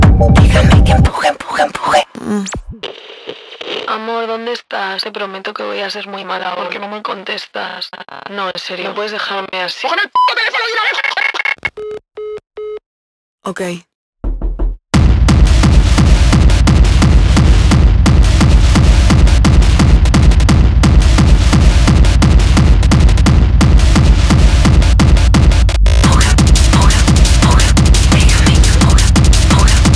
Жанр: Танцевальные / Электроника / Техно